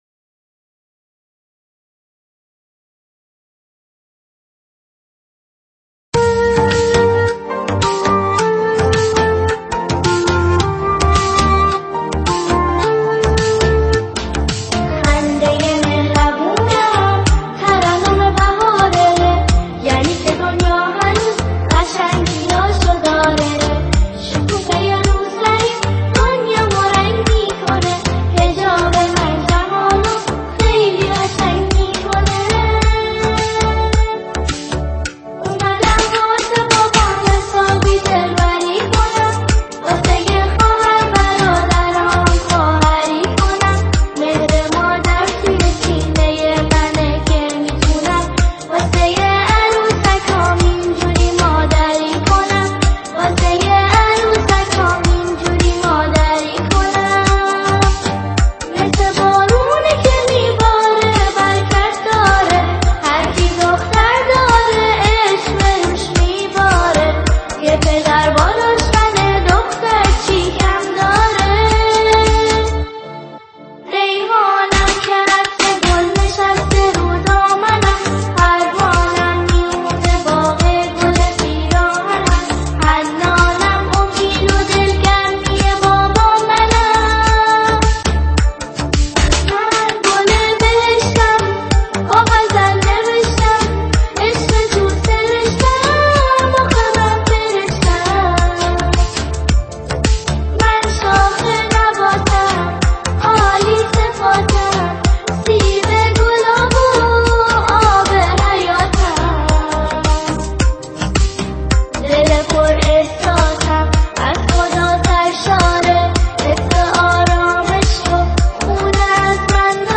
ژانر: سرود ، سرود مناسبتی